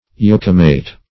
Yokemate \Yoke"mate`\, n.